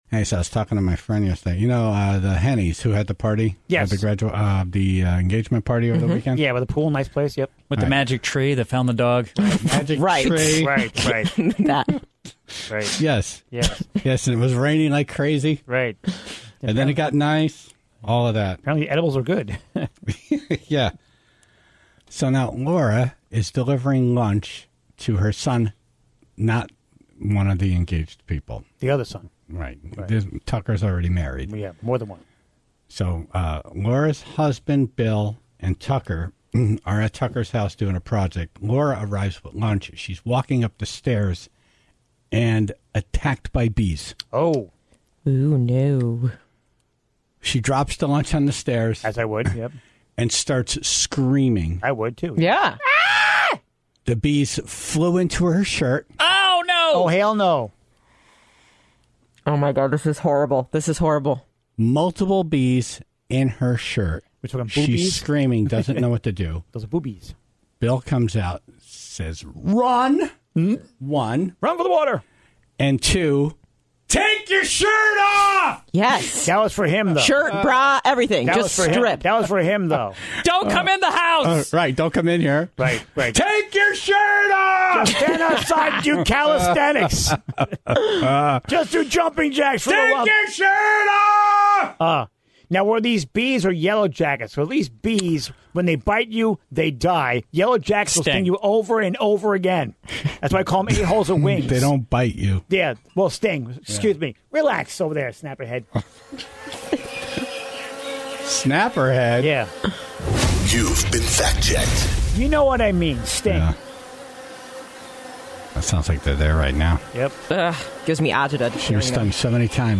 The Tribe called in their stories, including a guy that accidentally swallowed a hornet from his coffee cup.